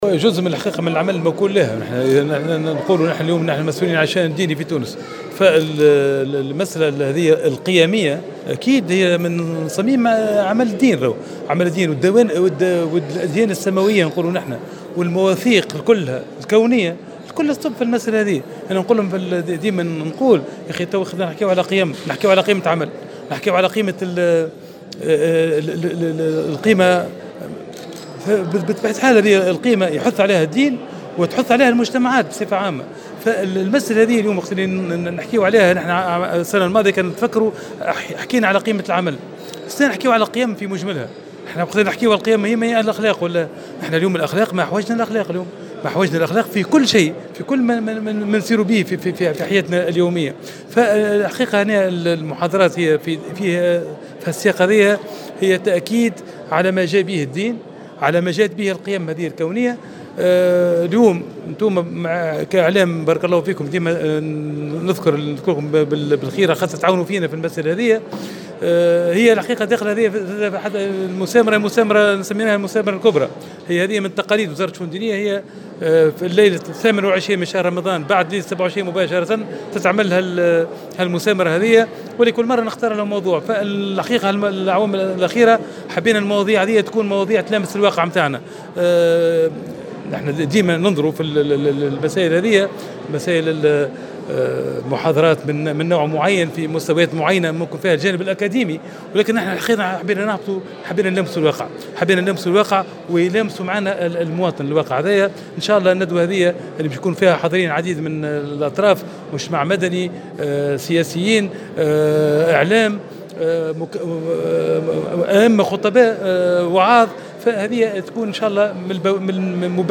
أكد وزير الشؤون الدينية أحمد عظوم، مساء اليوم السبت، على هامش مسامرة دينية حول القيم الانسانية الثابتة، أهمية الأخلاق في المجتمعات.
وعلق بالقول في تصريح لمراسل "الجوهرة اف أم": "ما أحوجنا للاخلاق اليوم"، مؤكدا أهمية مثل هذه المواضيع بالنسبة للحياة اليومية للمواطن.